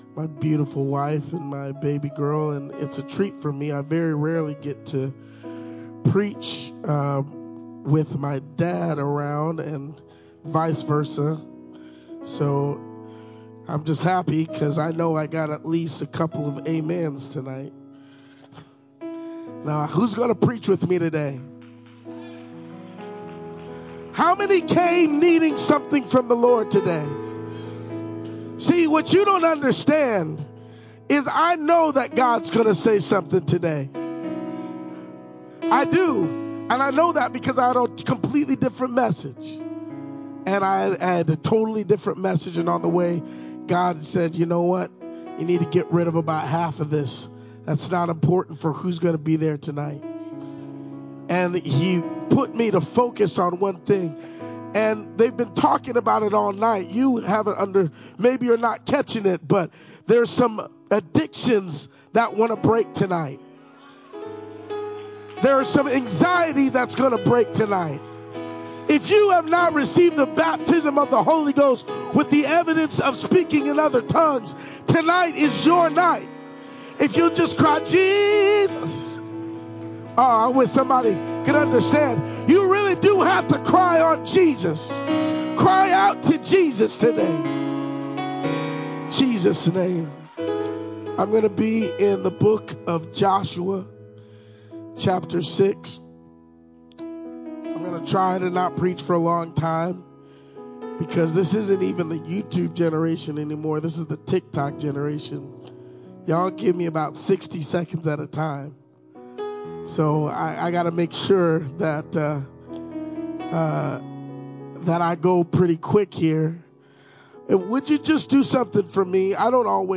Friday-Youth-Rally-CD.mp3